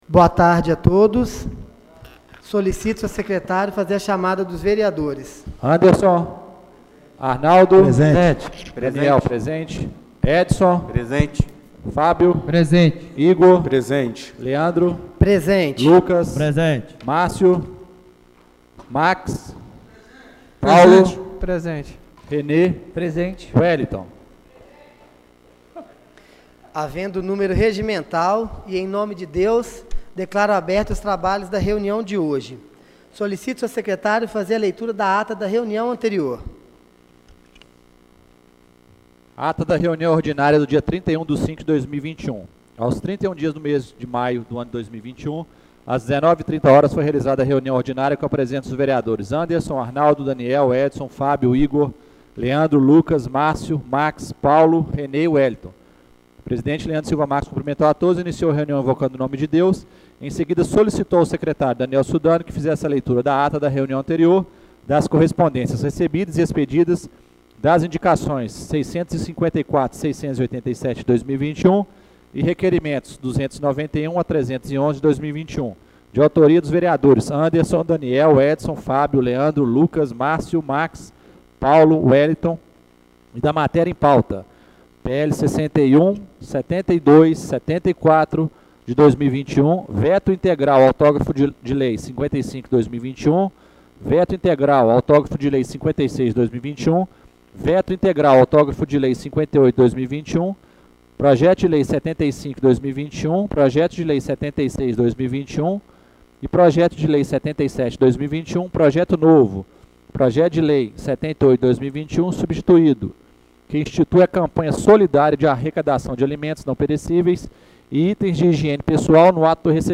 Reunião Extraordinária do dia 02/06/2021